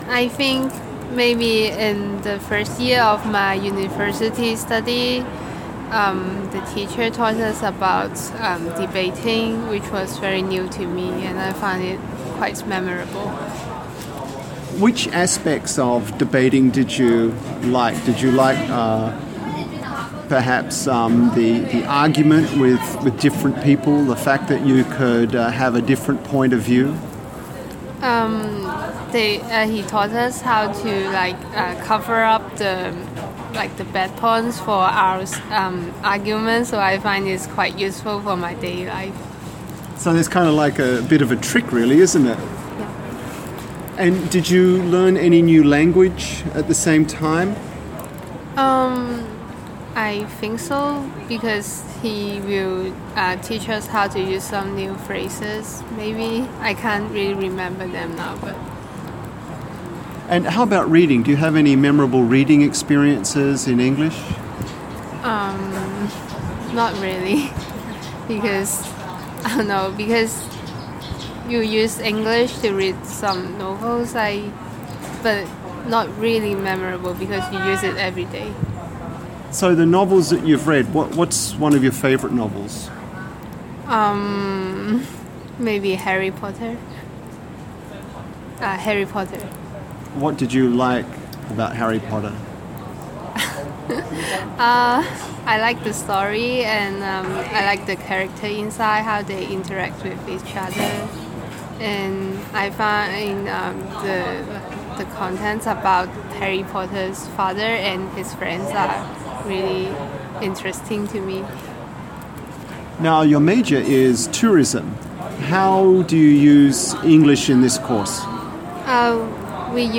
Subcategory: Fiction, Reading, Speech